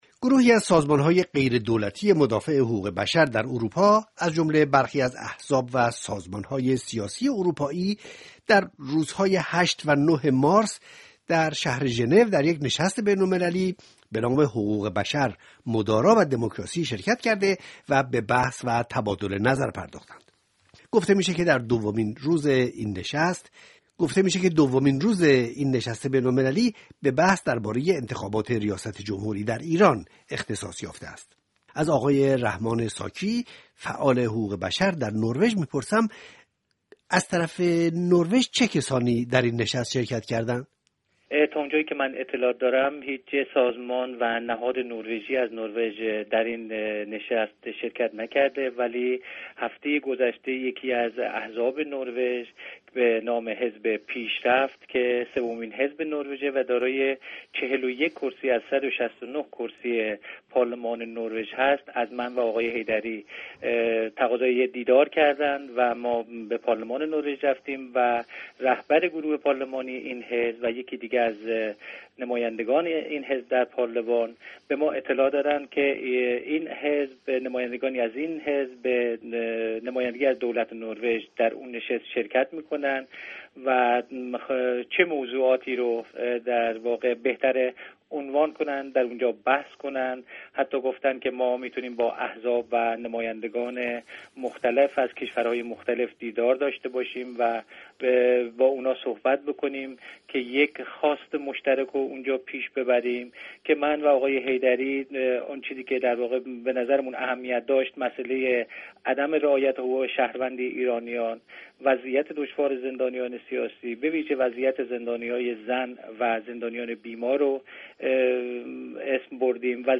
گفت و گوی رادیوی بین المللی فرانسه